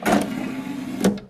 Apertura de un lector de cd
Sonidos: Acciones humanas
Reproductor de CD